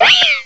cry_not_meowstic.aif